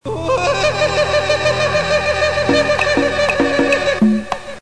Kermis geluid Afrikaans Jodelen
Categorie: Geluidseffecten
geluidseffecten, kermis geluiden
kermis-geluid-afrikaans-jodelen-nl-www_tiengdong_com.mp3